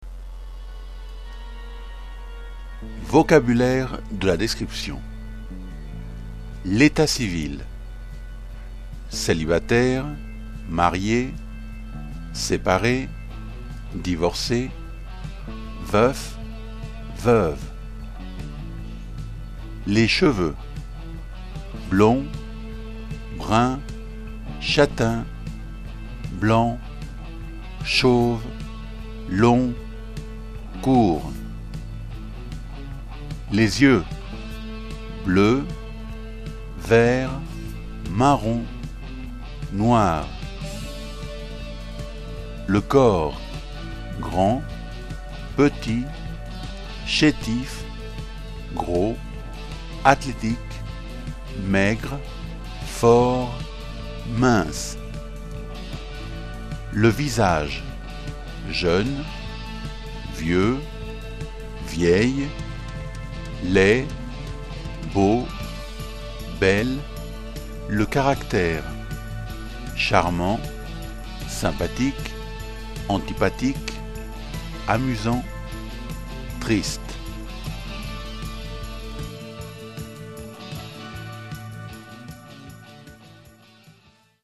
Puedes oír este vocabulario para saber cómo es la pronunciación de todas estas palabras descriptivas.